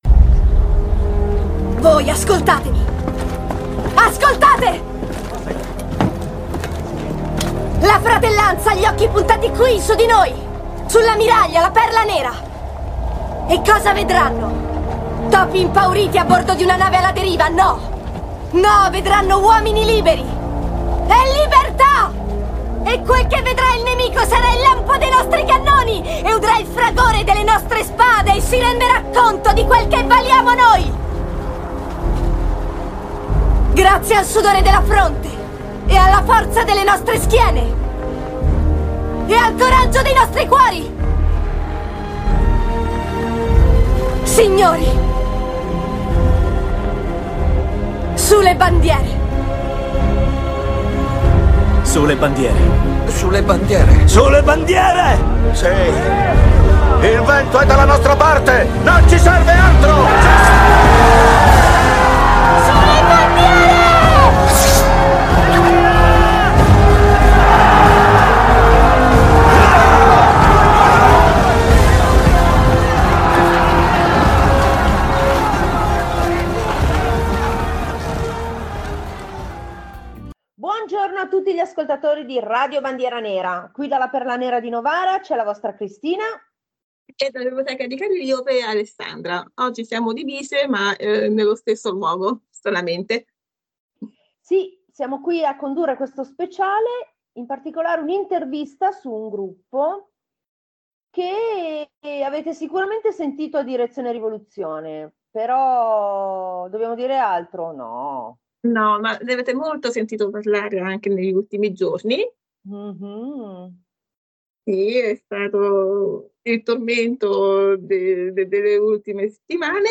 Intervista ai Wild Alley
Volete conoscere qualche cosa in più del vostro gruppo preferito? Allora non vi perdete la nostra intervista semiseria con i Wild Alley!